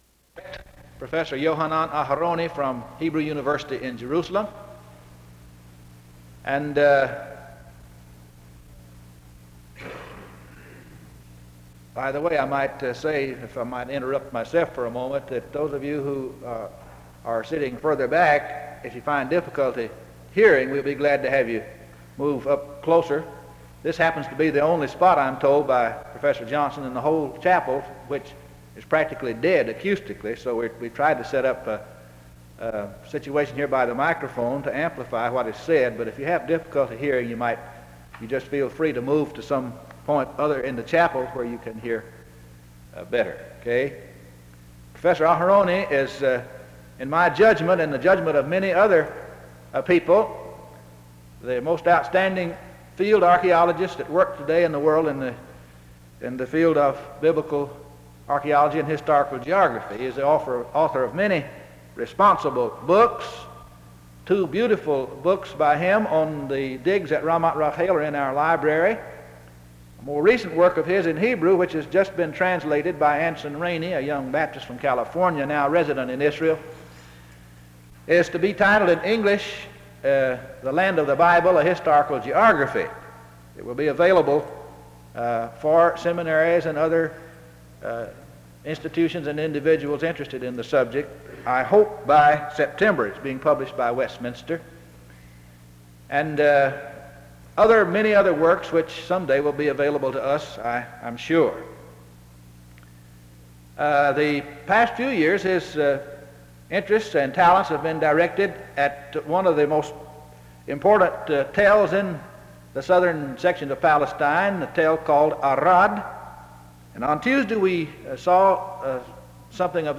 File Set | SEBTS_Chapel_Yohanan_Aharoni_1966-04-14.wav | ID: d06c5637-bc60-45b6-ab9e-e2393ff0591e | Hyrax